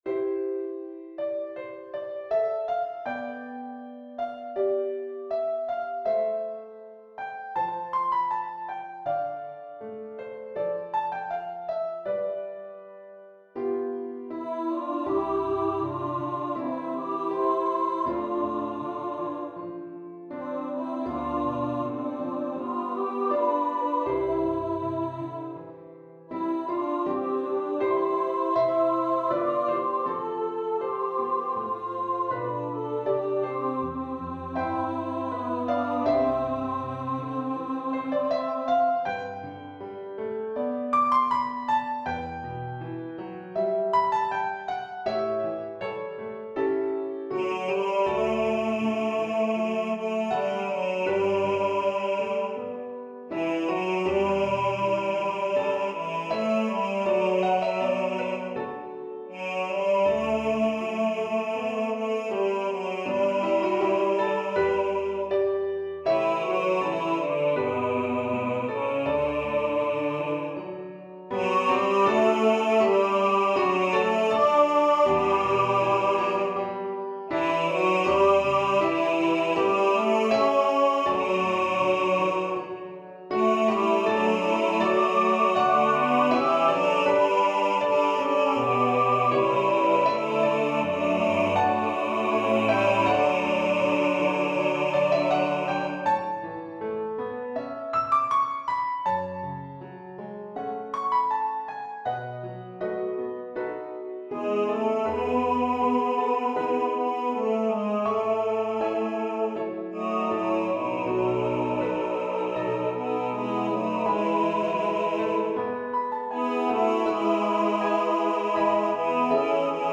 Let All Thy Will Be Mine (2025) for SATB Choir & Piano
Synthesized MP3 file: